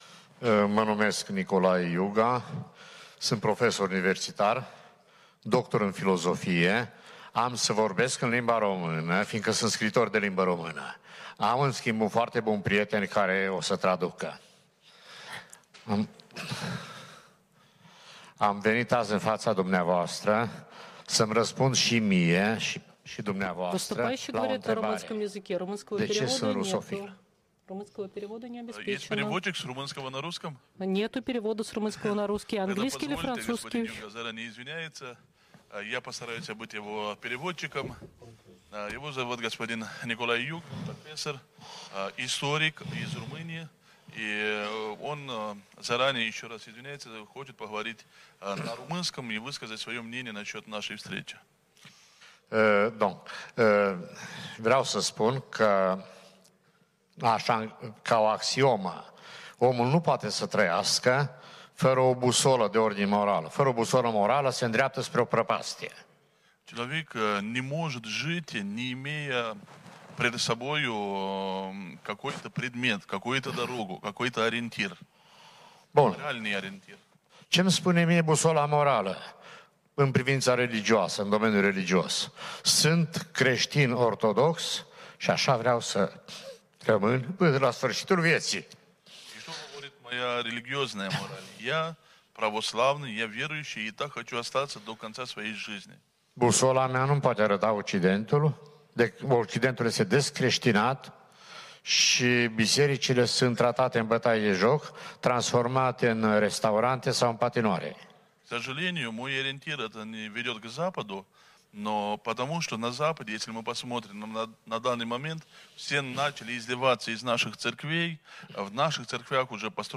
Форум многополярности: Секция традиционные ценности